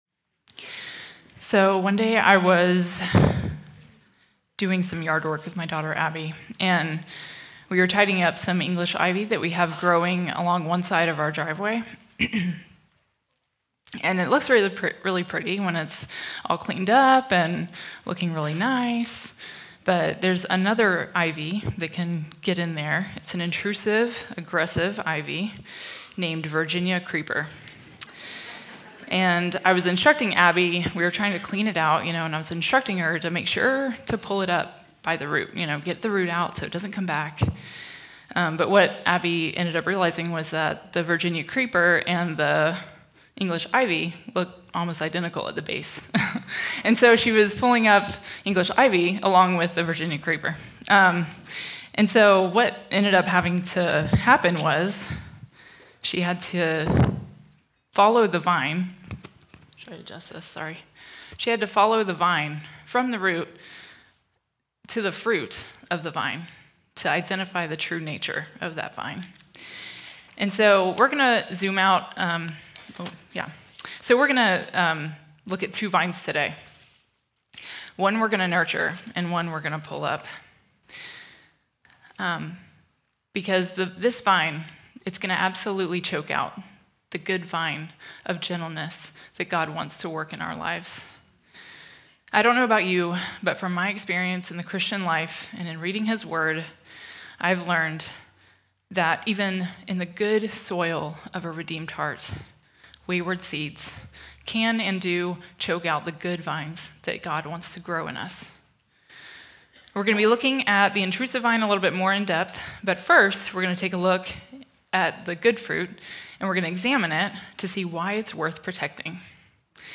Home » Sermons » Fruit of Gentleness
2026 DSWG Conference: Women's Gathering Date